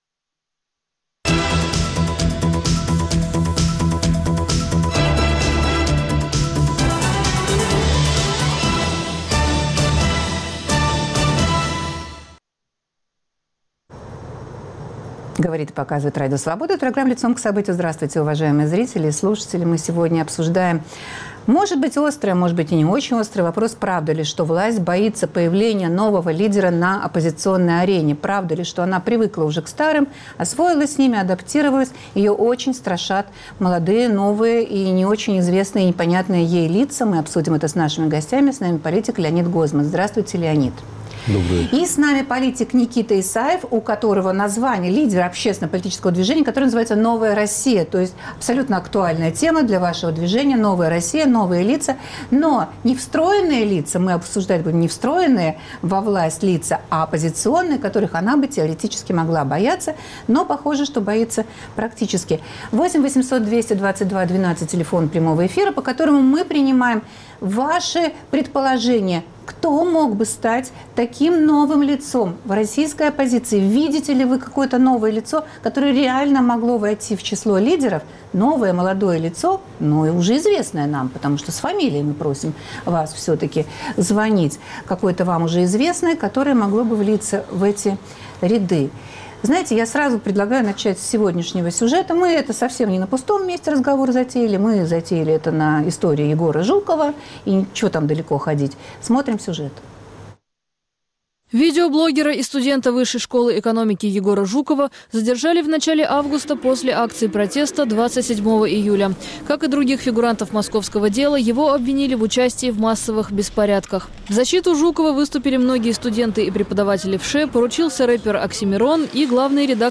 Кремль в самом деле боится, что во главе оппозиционных сил встанет неподконтрольный ему политик? Обсуждают политики Леонид Гозман и Никита Исаев.